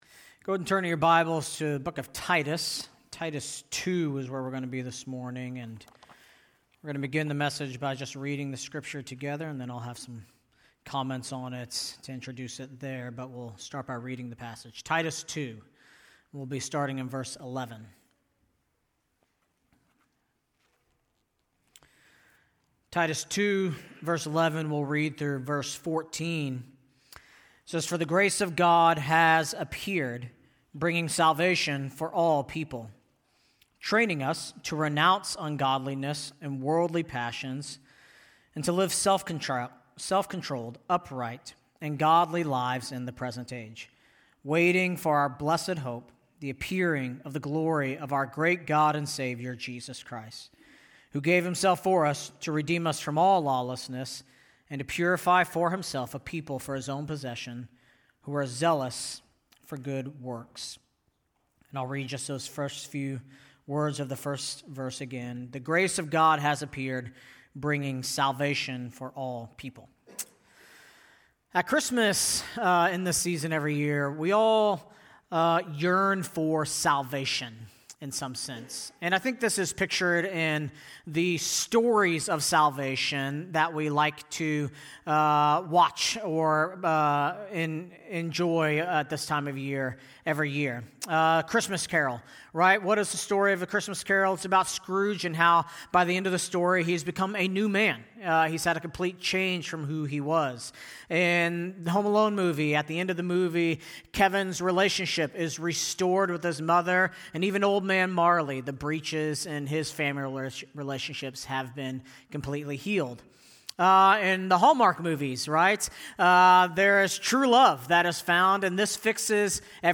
December 7, 2025 (Sunday Morning)